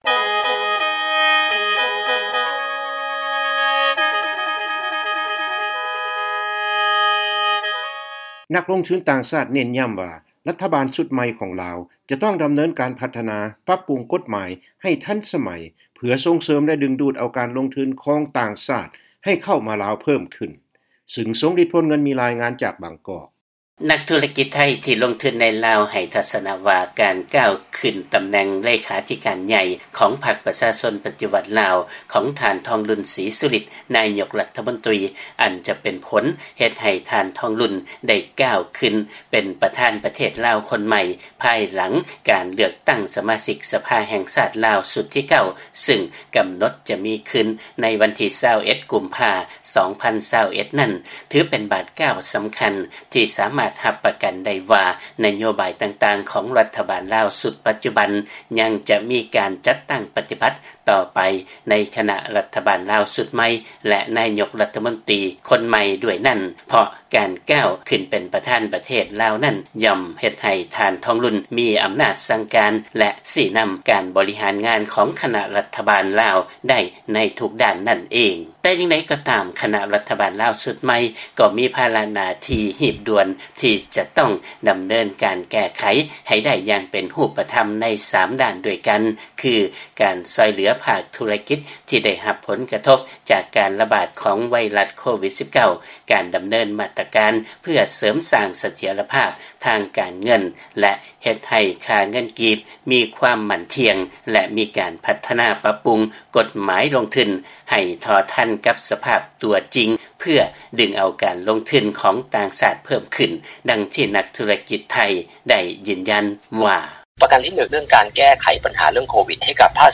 ຟັງລາຍງານ ນັກລົງທຶນຊາວຕ່າງຊາດ ເນັ້ນຢໍ້າວ່າ ລັດຖະບານຊຸດໃໝ່ຂອງ ລາວ ຈະຕ້ອງດຳເນີນການພັດທະນາ ປັບປຸງກົດໝາຍໃຫ້ທັນສະໄໝ